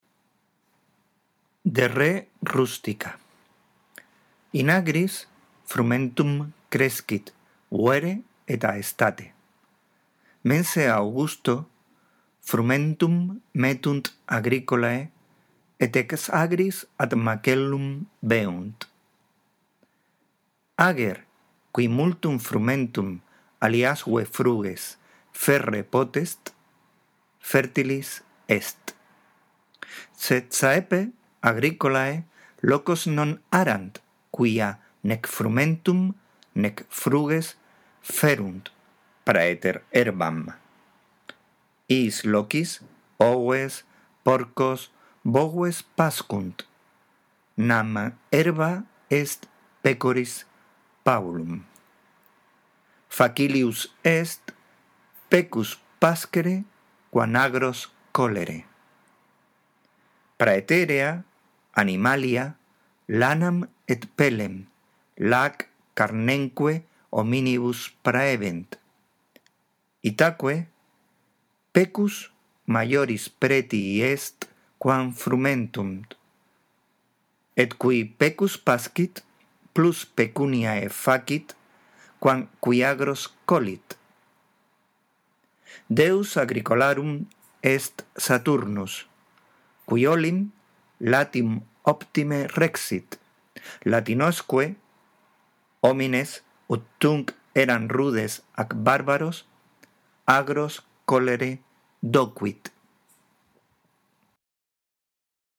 La audición de este archivo te ayudará en la práctica de la lectura del latín